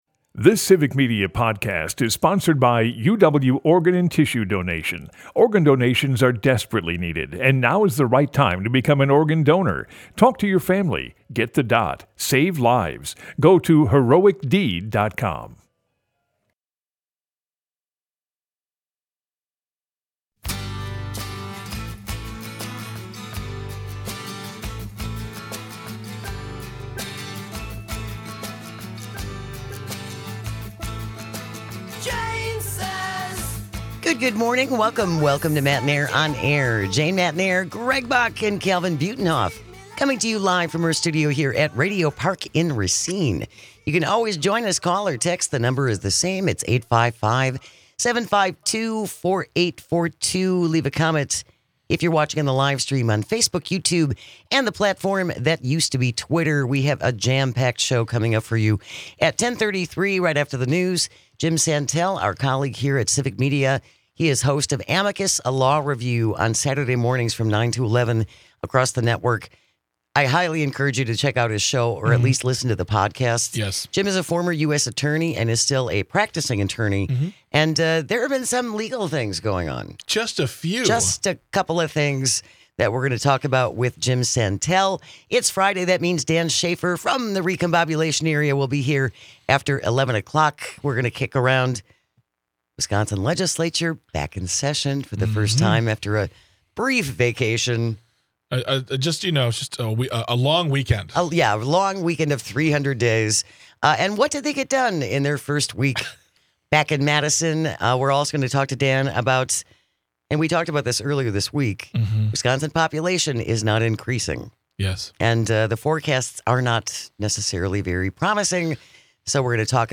They cover all the news that affects you with humor and a unique perspective.